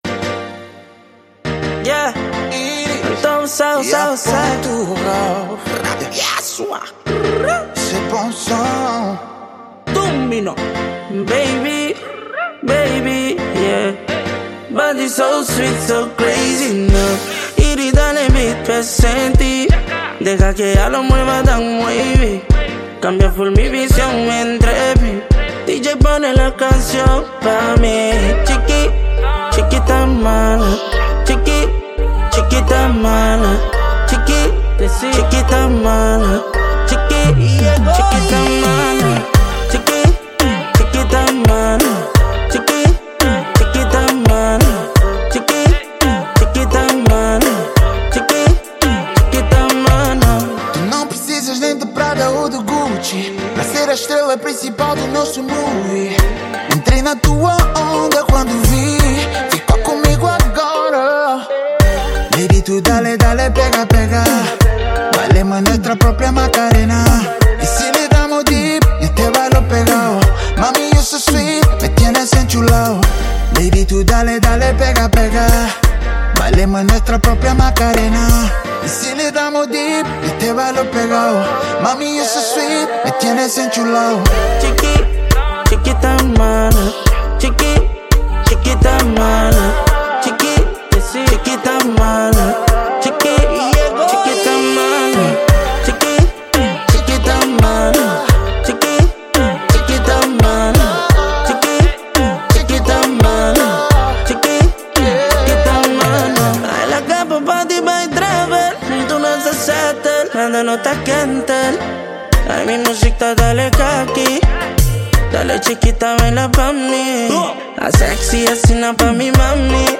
Gênero: Reggaeton